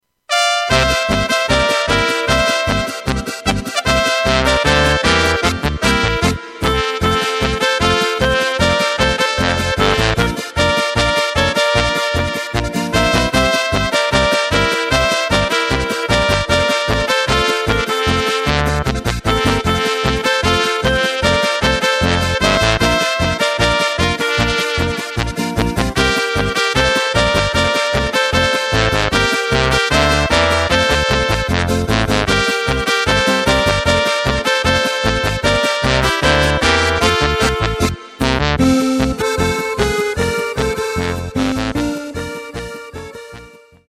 Takt:          2/4
Tempo:         152.00
Tonart:            C
Flotte Polka aus dem Jahr 2018!